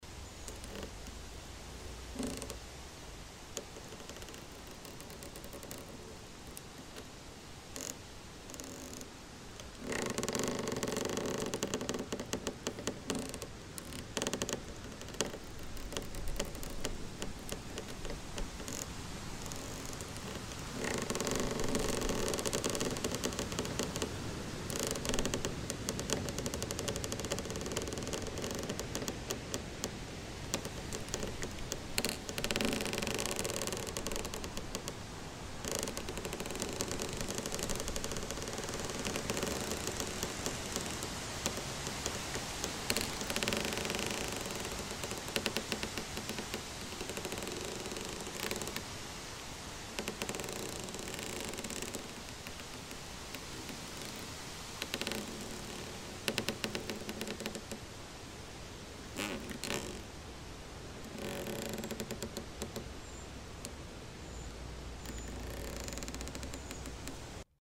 Звуки растущего дерева
На этой странице представлены редкие звуки растущего дерева — от едва уловимого шепота молодых побегов до глубоких вибраций старого ствола.